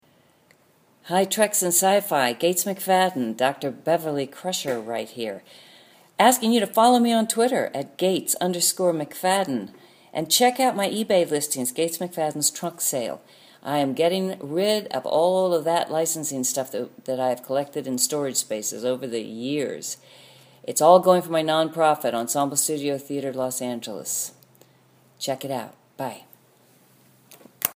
Audio from Gates: